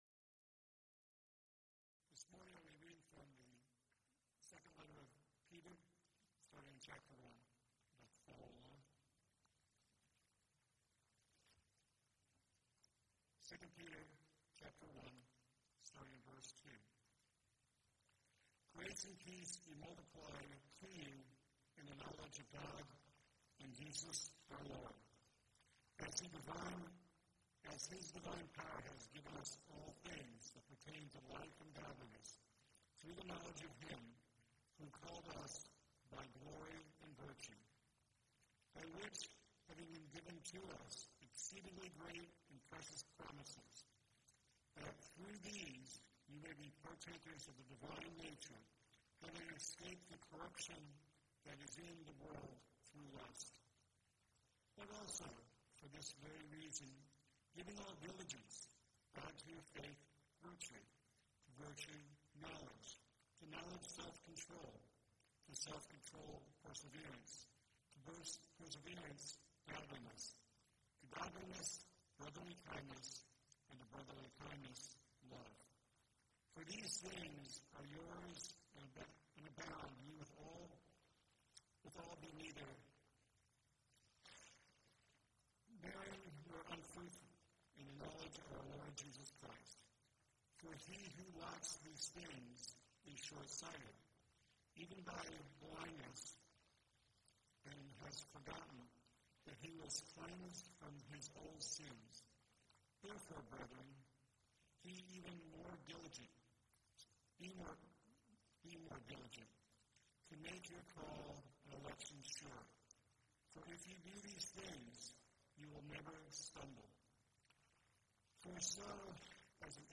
Event: 2nd Annual Arise Workshop
lecture